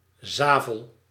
The Sablon (French, pronounced [sablɔ̃] ) or Zavel (Dutch, pronounced [ˈzaːvəl]